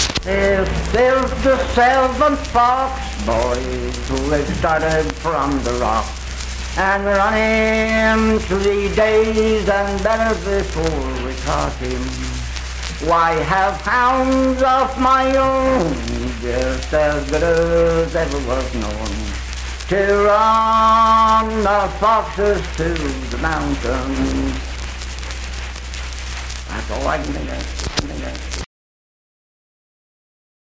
Unaccompanied vocal music performance
Miscellaneous--Musical
Voice (sung)